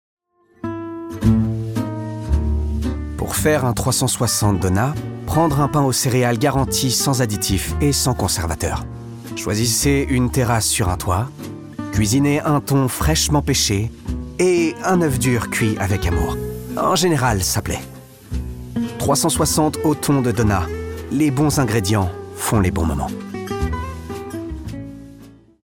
Pub Daunat